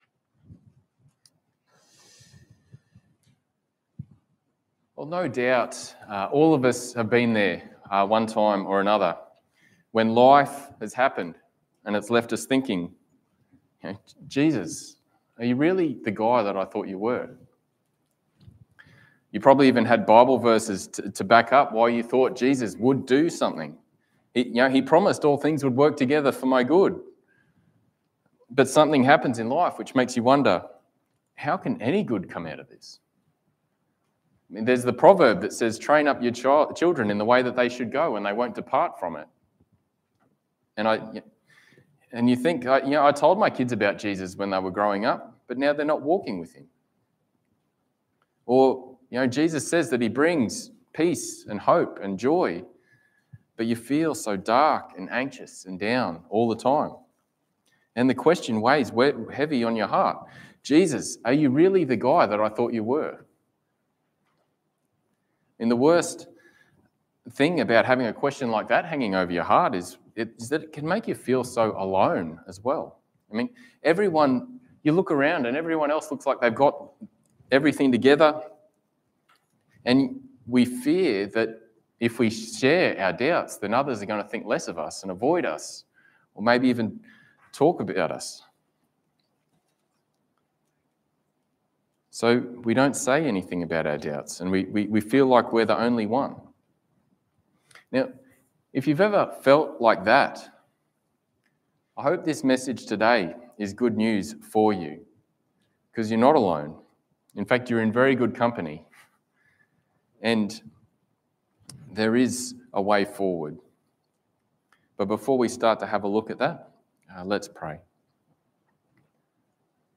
Passage: Luke 7:18-35 Service Type: Sunday Morning